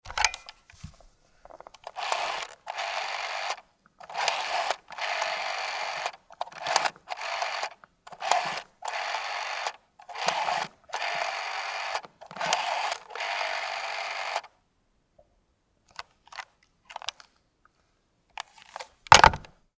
Telefon
Typ, Name: miniset 111 Hersteller, Marke: Siemens